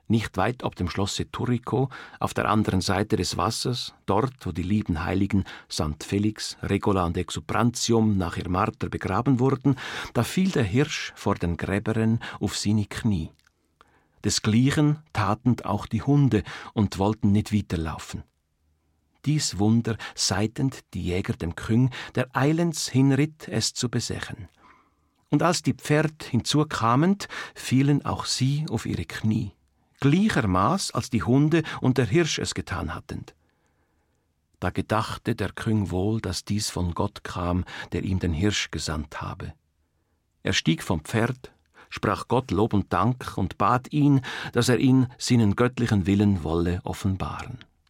In diesem Auszug aus den Schweizer Chroniken von Heinrich Brennwald (1508-1516), entscheidet sich Karl der Grosse, einem Hirsch nach Zürich zu folgen. Text in Schweizer Dialekt jener Zeit.